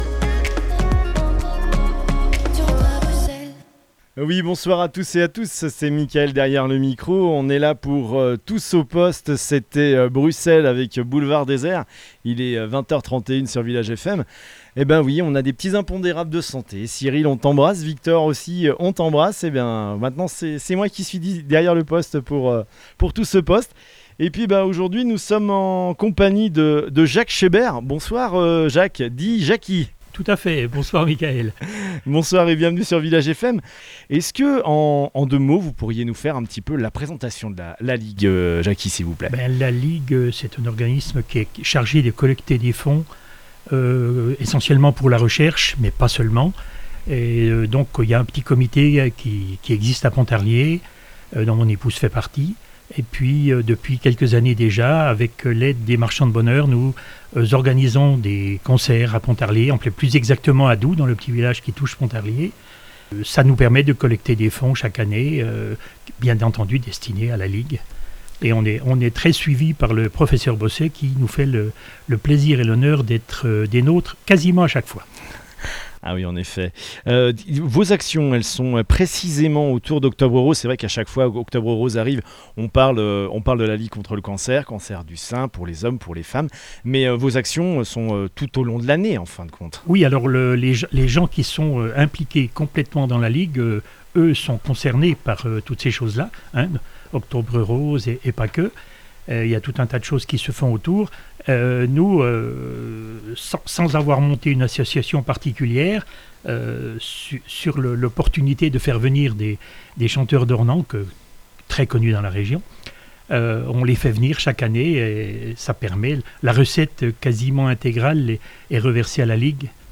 Pour leur 12e spectacle dans le Haut-Doubs, les chanteurs d'Ornans se produisent en l'église de Doubs...